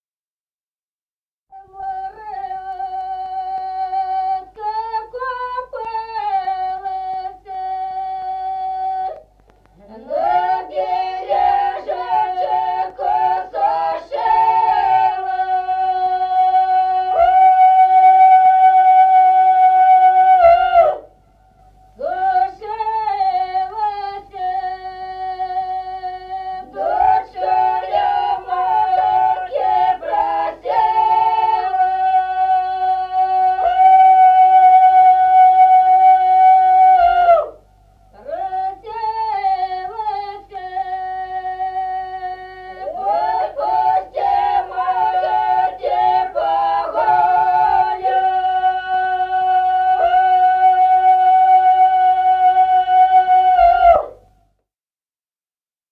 Народные песни Стародубского района «На море утка купалася», весняная девичья.
с. Остроглядово.